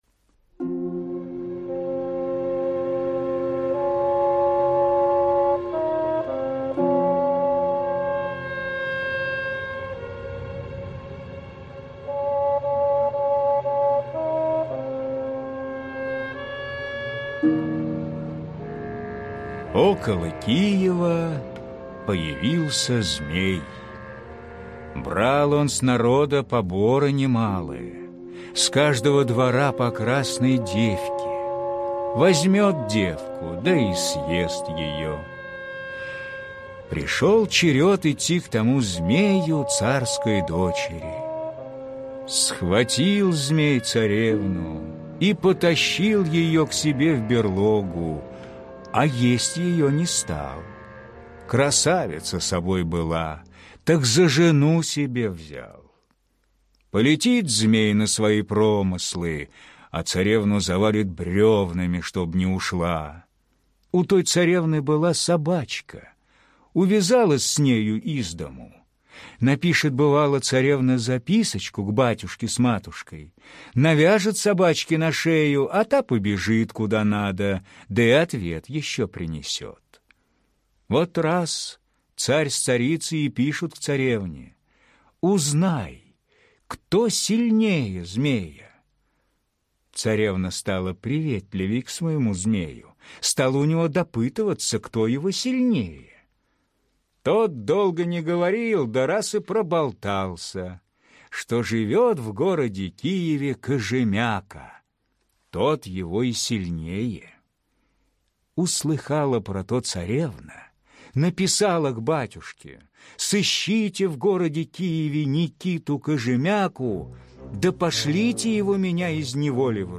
Никита Кожемяка – русская народная аудиосказка